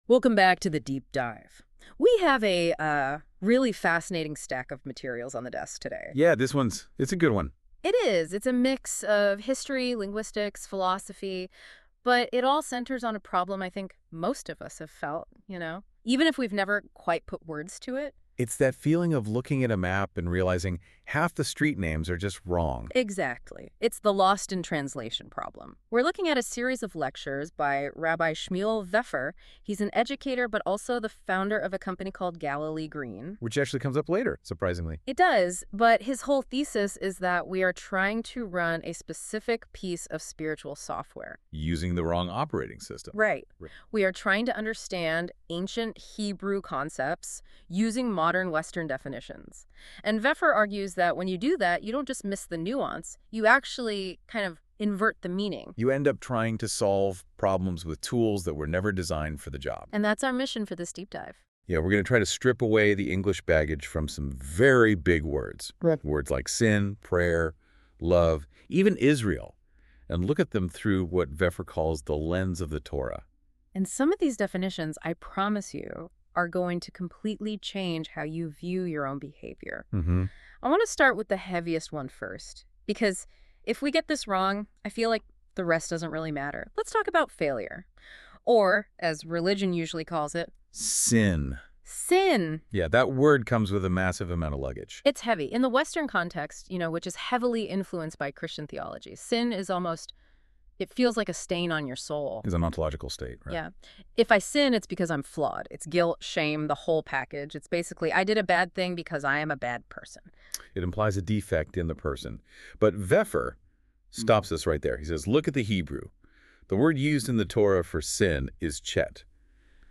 (note: this podcast was produced with the help of NotebookLM) Here’s a link to the videos discussed in this podcast.